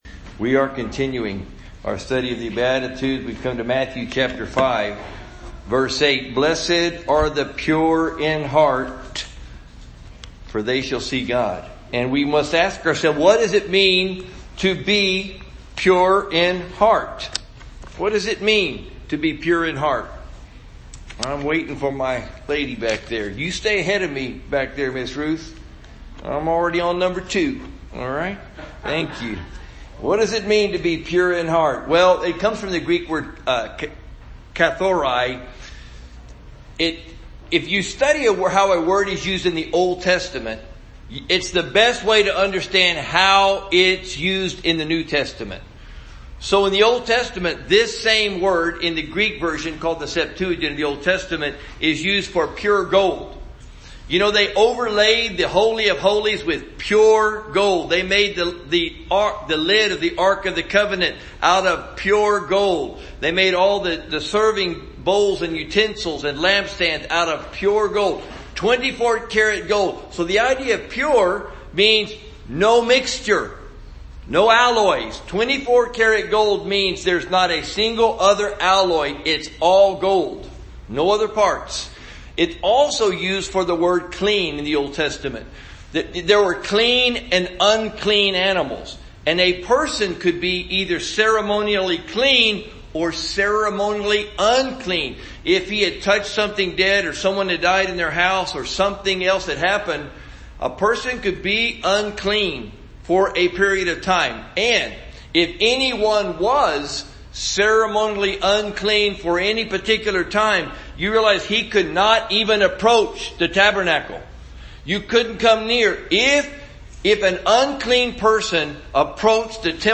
Sunday Message - University Park Baptist